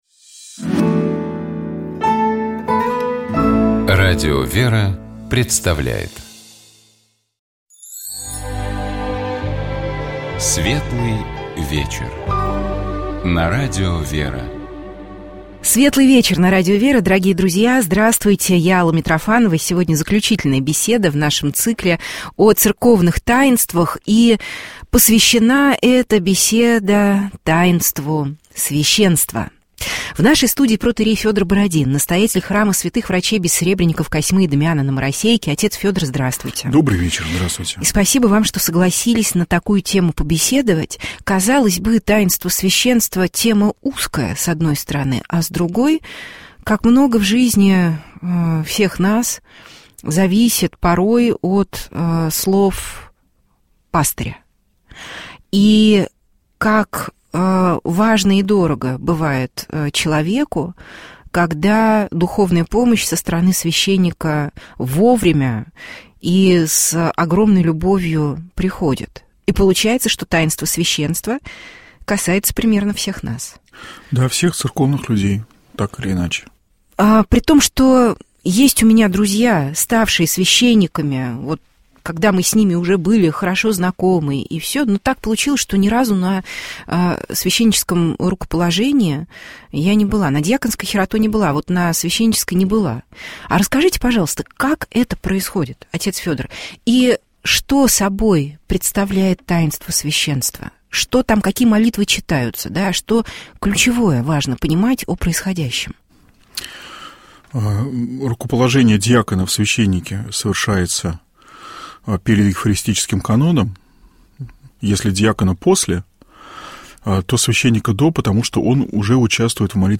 О человеке как творении Божьем — епископ Тольяттинский и Жигулёвский Нестор.